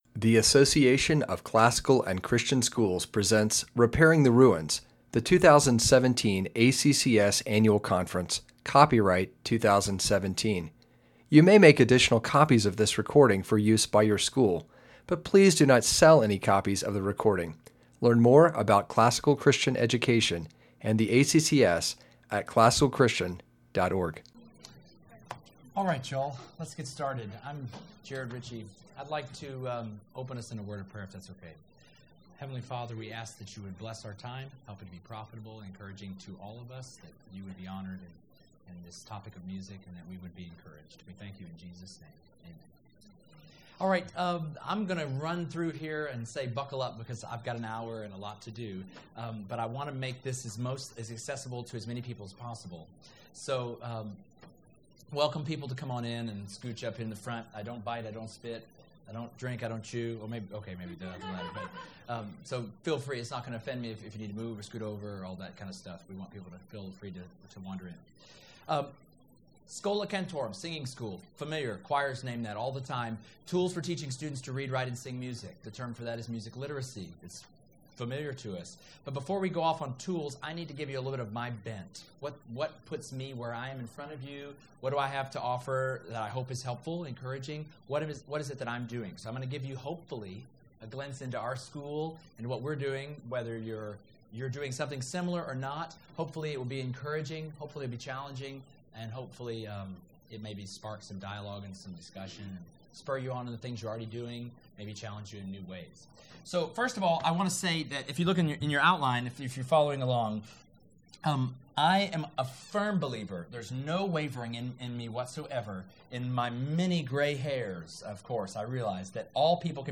2017 Workshop Talk | 1:05:53 | All Grade Levels, Art & Music
For music literacy to grow in the classical Christian school, students must be taught to read, write, and sing in the language of music. In this session, the basics of classroom music literacy training will be demonstrated as participants see, hear, and sing through a number of resources ready for use in the classical Christian school model.